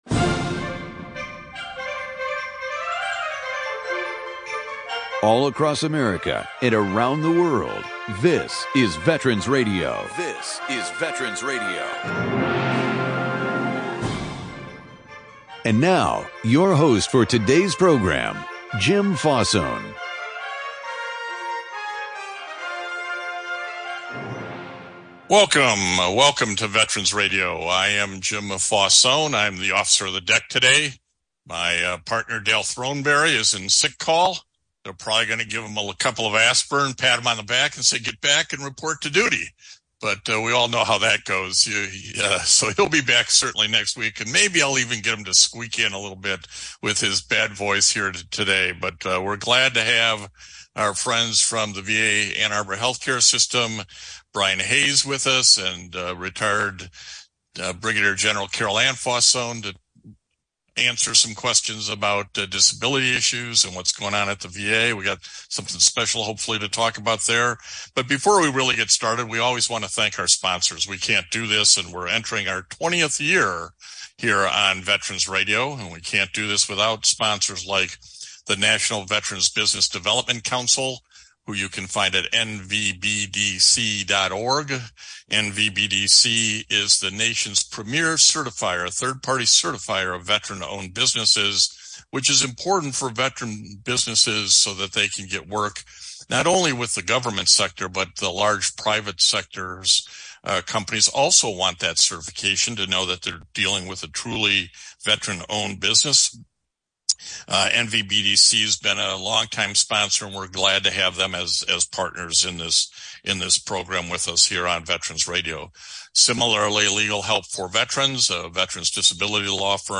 VA Benefits Q&A - Focusing on women in the military this month.
Call in during the program to ask your question or offer a comment.